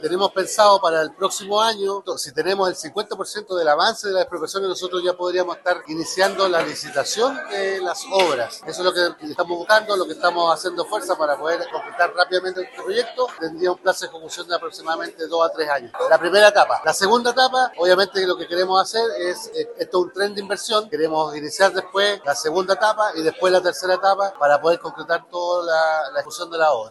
Al respecto, el director regional de Vialidad, Jorge Loncomilla, aseguró que están acelerando lo más posible los trámites administrativos para que comiencen las faenas.
jorge-loncomilla-director-vialidad-cuna.mp3